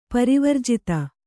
♪ parivarjita